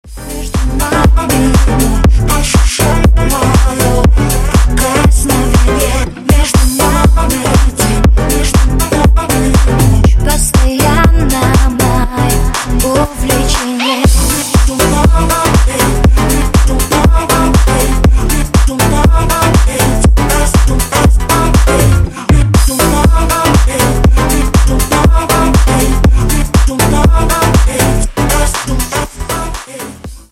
Клубные Рингтоны
Рингтоны Ремиксы » # Танцевальные Рингтоны